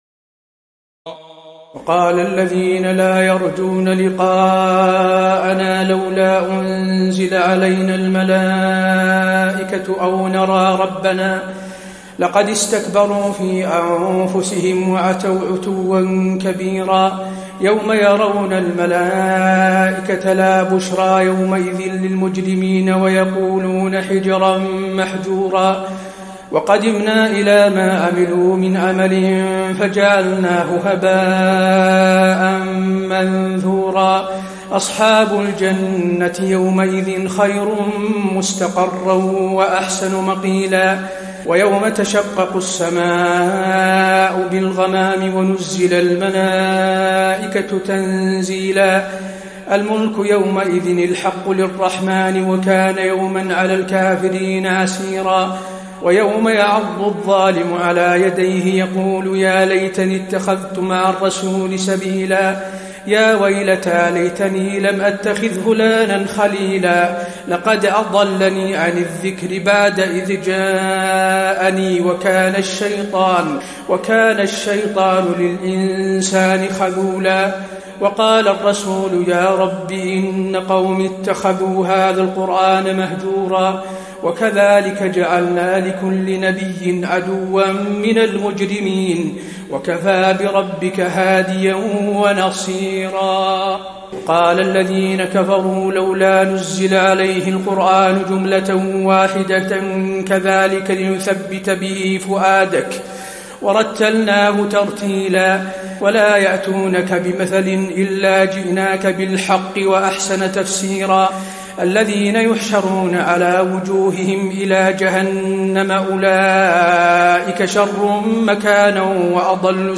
تراويح الليلة الثامنة عشر رمضان 1435هـ من سورتي الفرقان (21-77) و الشعراء (1-104) Taraweeh 18 st night Ramadan 1435H from Surah Al-Furqaan and Ash-Shu'araa > تراويح الحرم النبوي عام 1435 🕌 > التراويح - تلاوات الحرمين